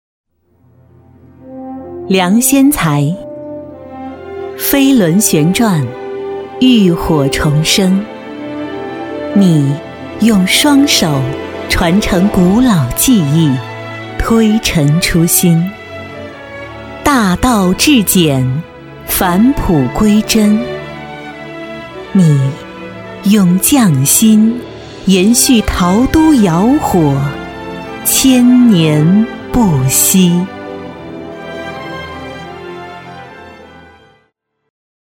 国语青年积极向上 、时尚活力 、神秘性感 、调性走心 、亲切甜美 、女广告 、500元/条女S143 国语 女声 广告-浩富-活力 积极向上|时尚活力|神秘性感|调性走心|亲切甜美